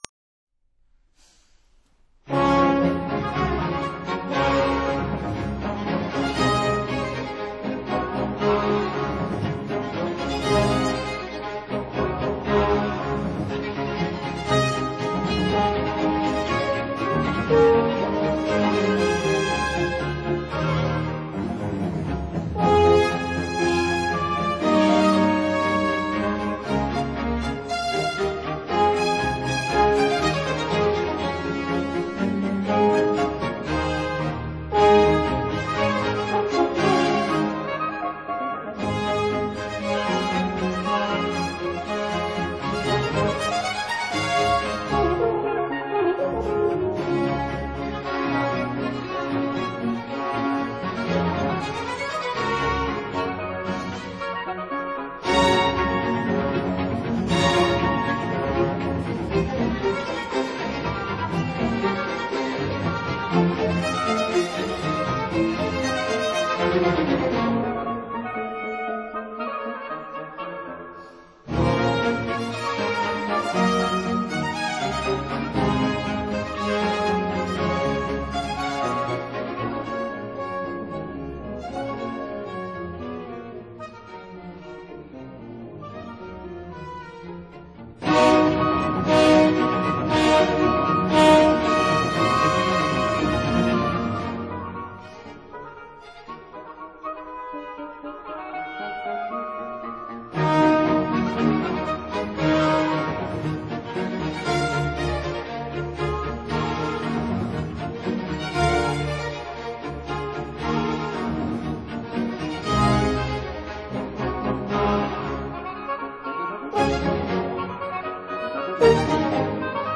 Allegro e maestoso
Andante
Vivace